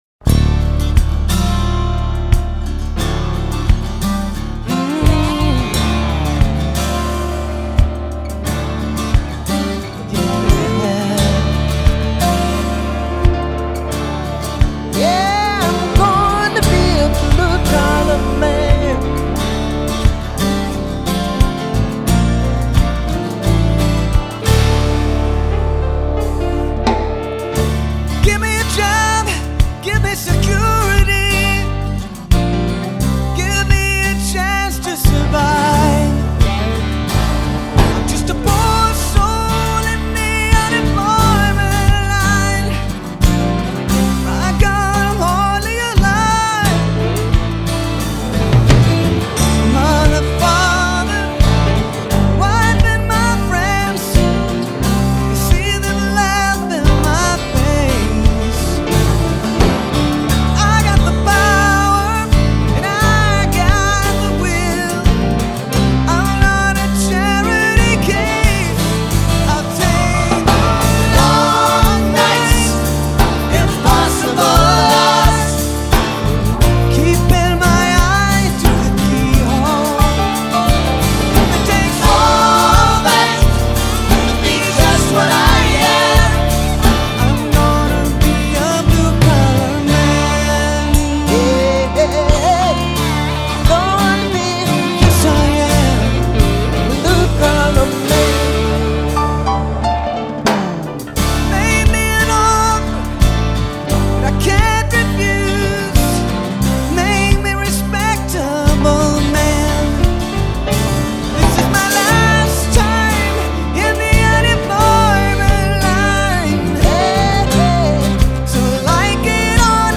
soul-infused reading